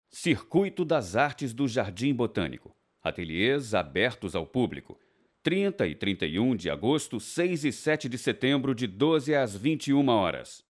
Masculino
Chamada para Rádio e TV sem BG - Circuito das Artes do Jardi
Voz Varejo